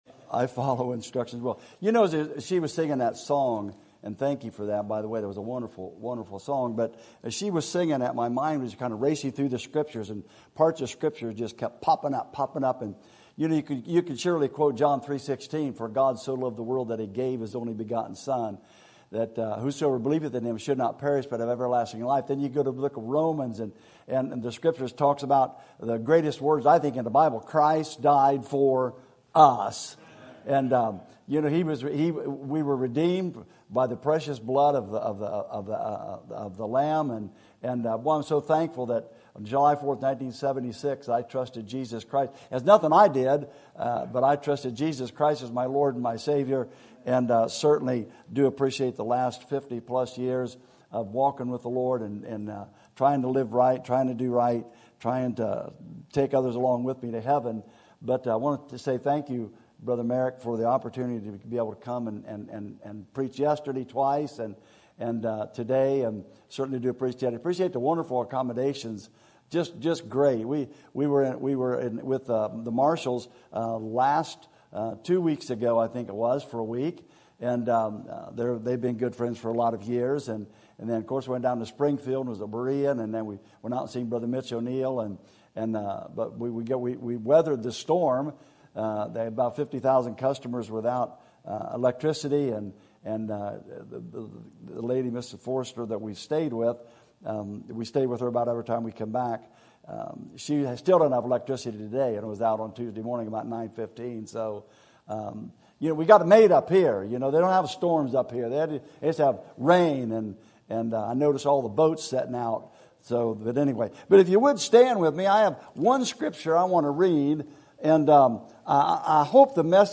Sermon Topic: General Sermon Type: Service Sermon Audio: Sermon download: Download (18.05 MB) Sermon Tags: Matthew Love Service Jesus